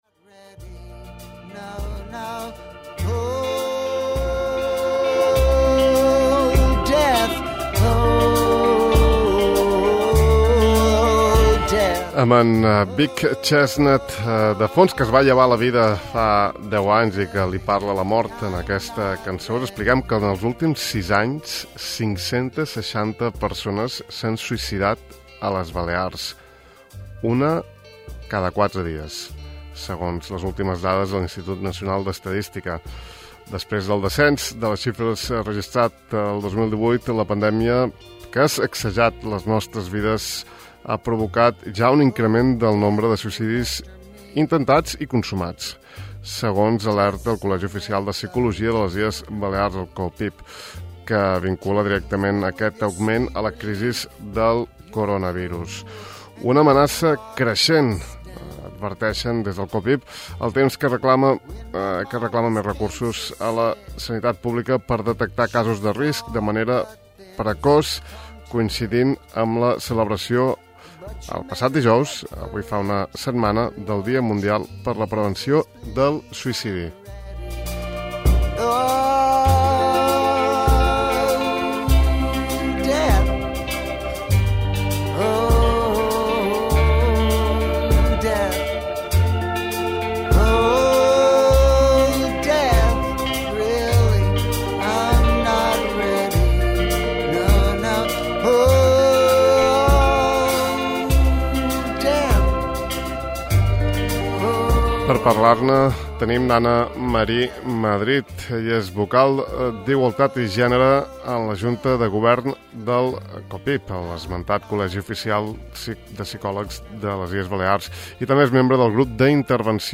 En l’entrevista ens diu que del suïcidi se n’ha de parlar i això és el que hem fet aquest matí.